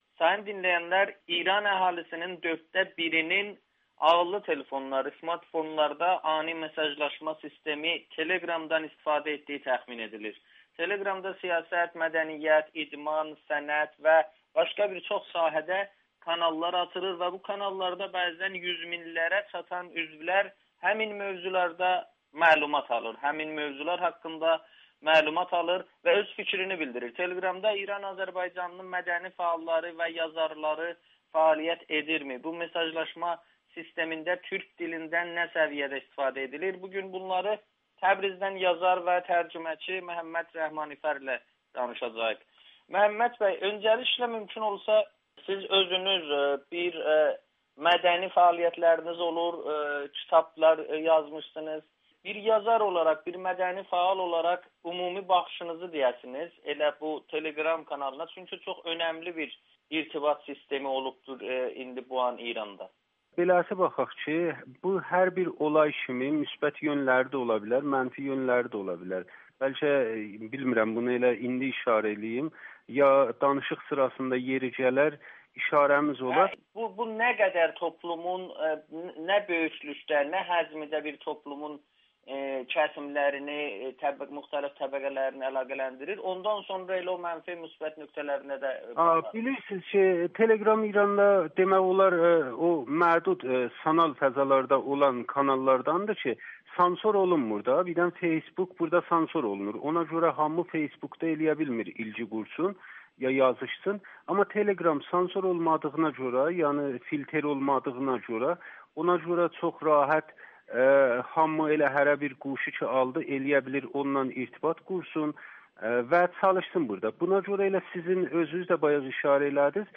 Ani mesajlaşma sistemi Telegram-ın İran əhalisinin dörddə biri tərəfindən istifadə edildiyi təxmin edilir. Təbrizdən Amerikanın Səsinə danışan yazar və tərcüməçi